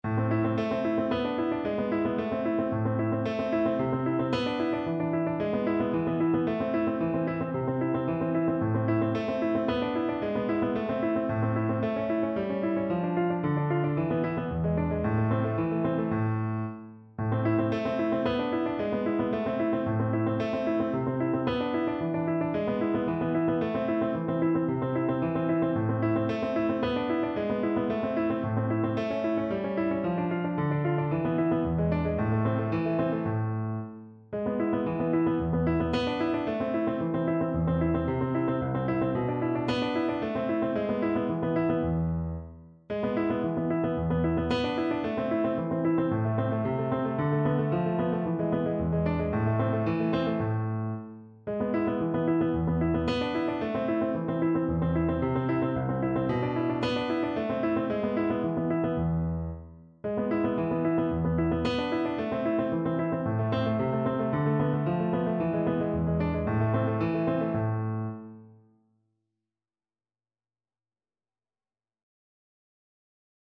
Classical Aguado, Dionisio Study No. 10 from Nuevo metodo para guitarra Piano version
No parts available for this pieces as it is for solo piano.
A minor (Sounding Pitch) (View more A minor Music for Piano )
4/4 (View more 4/4 Music)
Classical (View more Classical Piano Music)